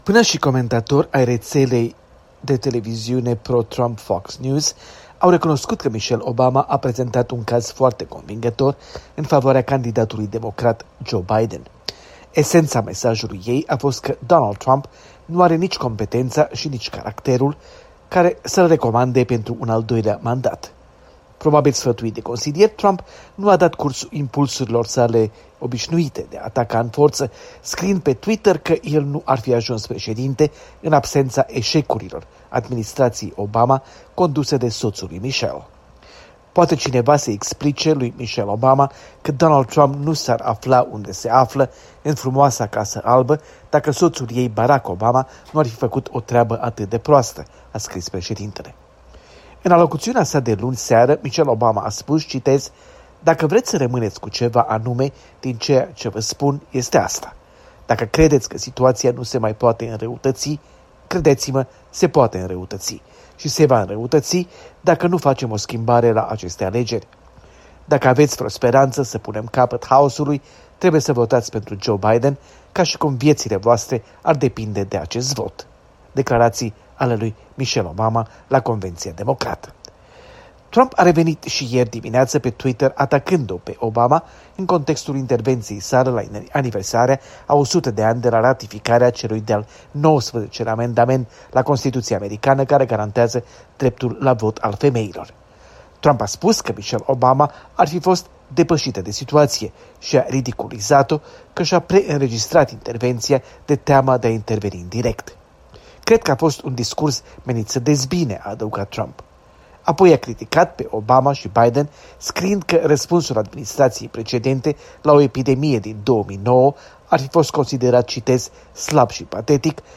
Corespondență de la Washington: Conveția Națională Democrată